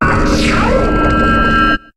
Cri de Spiritomb dans Pokémon HOME.